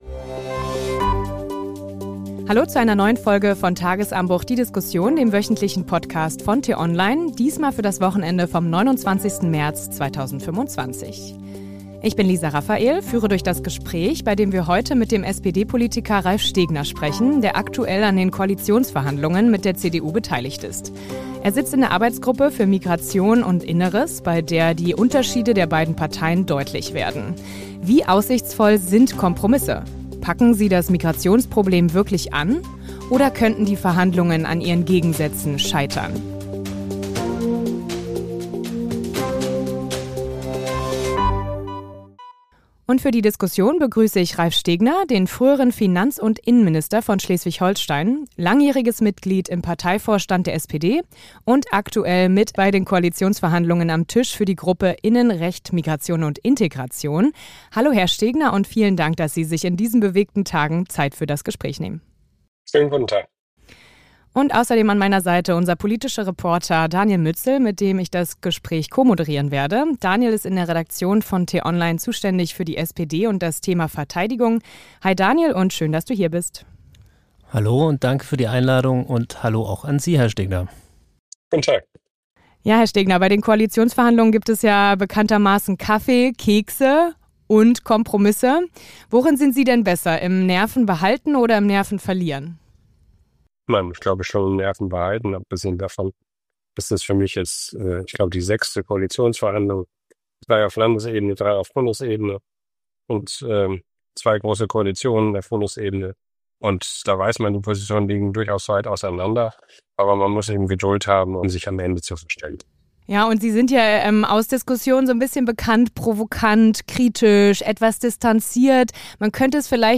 Den „Tagesanbruch“-Podcast gibt es immer montags bis samstags gegen 6 Uhr zum Start in den Tag – am Wochenende mit einer tiefgründigeren Diskussion.